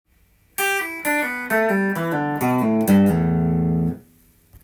プリング練習tab譜
譜面通り弾いてみました